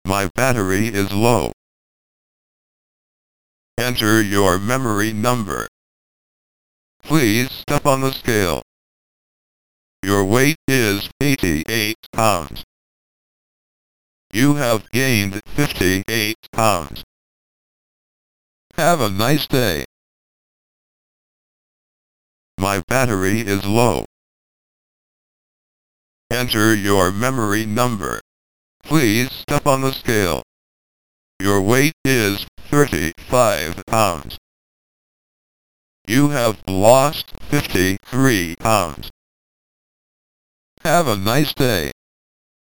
Weight Talker, a talking scale from 1985.
It has five memory positions so you can track how much you have gained or lost for up to five people, a guest button for weighing someone or something without affecting any of the memories, can weigh in pounds or kilograms, memory can be disabled, and when it shuts off it can either say "Have a nice day" or "Goodbye," user selectable.
In MAME, the weight to be measured is implemented using an analog dial which you use left and right arrows to adjust. Here, I play with it for less than a minute. I have it configured to think its batteries (7 AA cells on the older model or two nine-volt batteries on the newer model) are low.